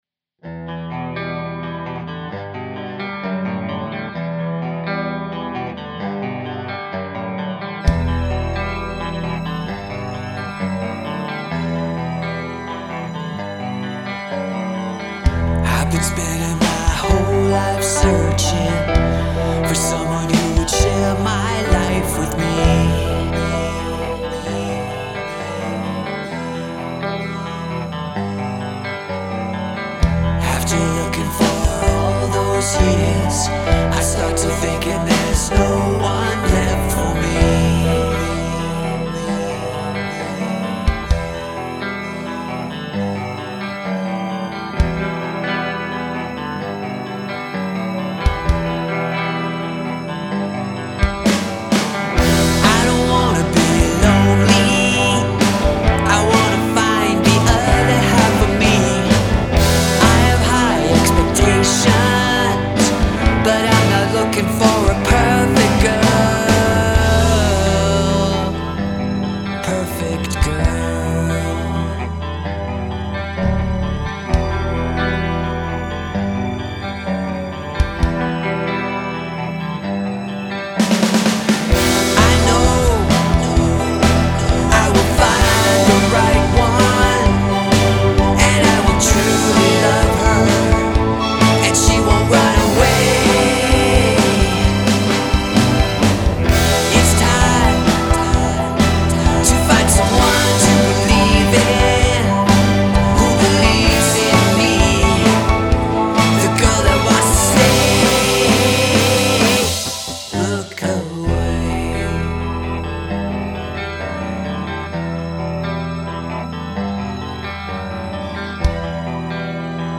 Yes, there are imperfections, but that's part of music.